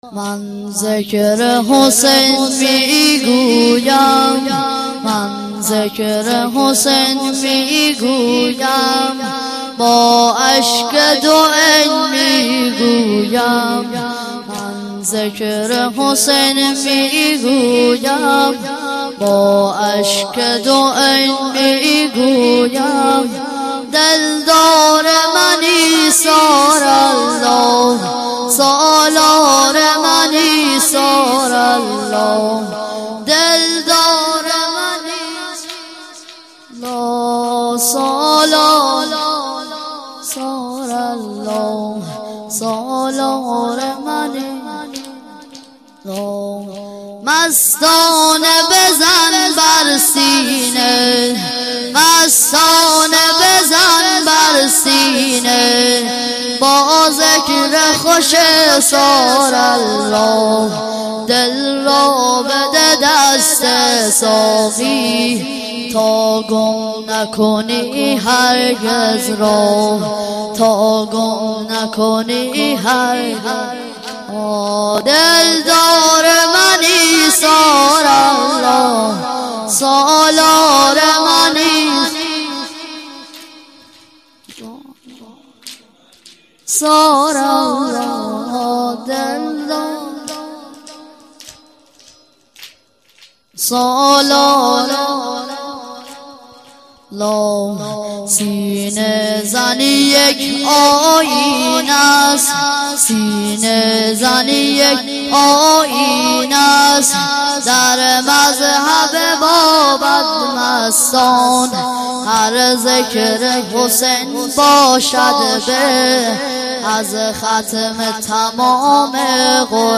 من ذکر حسین می گویم - مداح